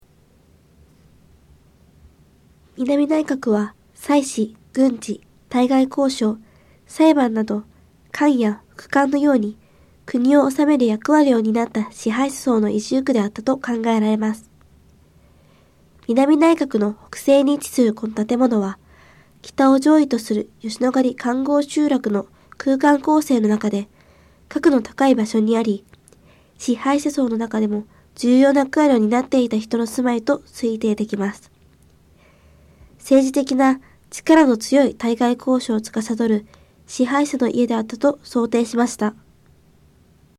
政治的な力の強い対外交渉を司る支配者の家であったと想定しました。 音声ガイド 前のページ 次のページ ケータイガイドトップへ (C)YOSHINOGARI HISTORICAL PARK